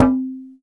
BARIMBA C2.wav